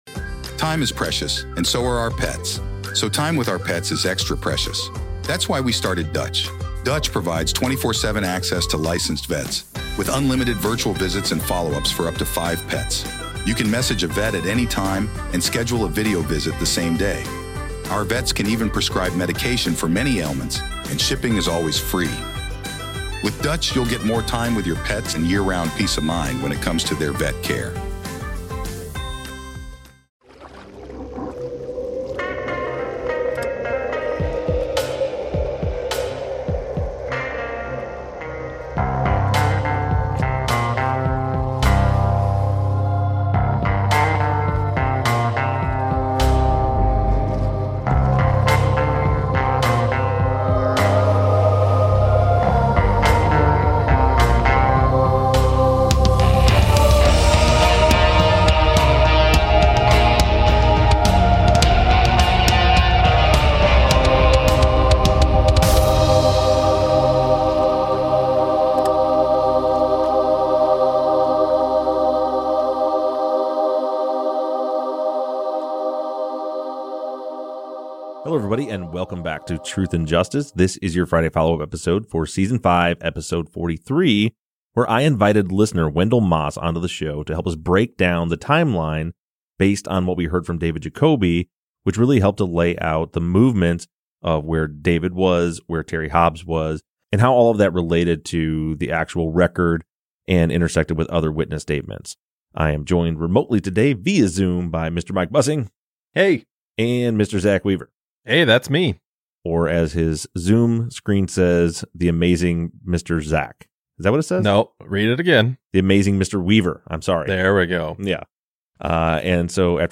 True Crime, Documentary, Society & Culture
The guys record a remote episode where they discuss listener questions on the Forgotten West Memphis Three case, as exclusive coverage of Season 5 draws to a close.